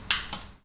gas_lamp
turn_off.ogg